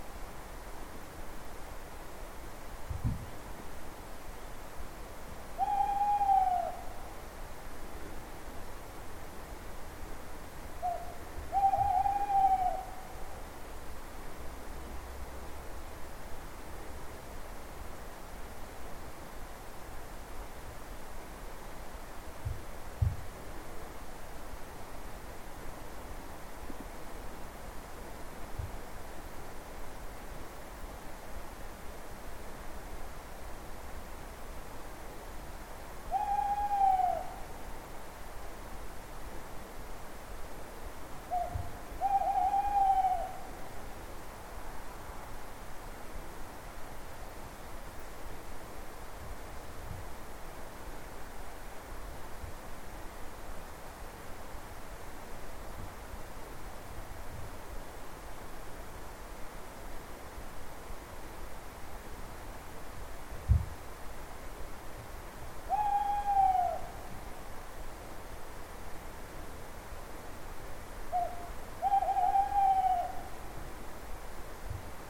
owl